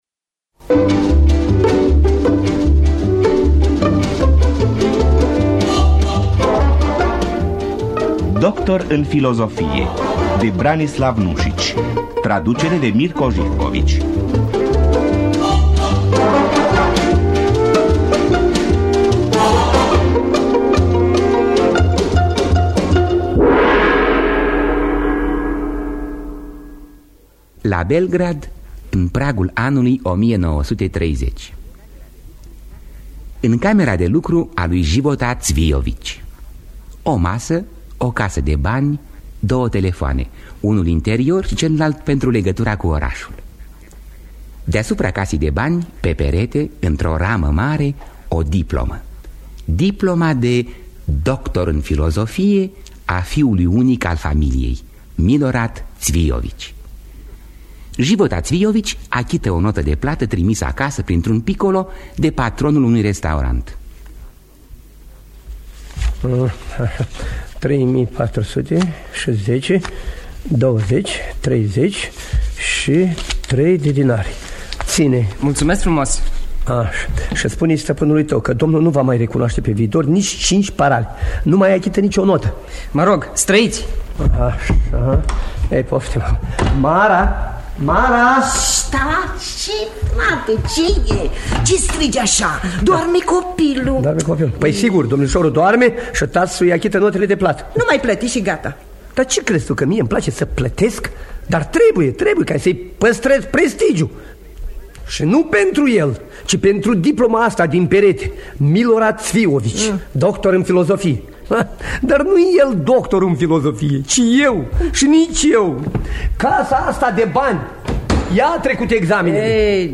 Doctor în filozofie de Branislav Nuşici – Teatru Radiofonic Online